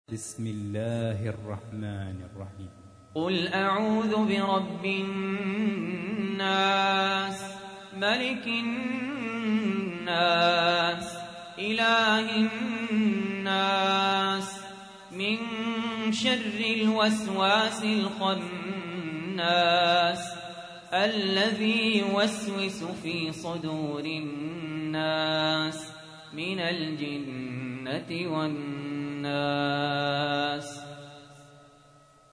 تحميل : 114. سورة الناس / القارئ سهل ياسين / القرآن الكريم / موقع يا حسين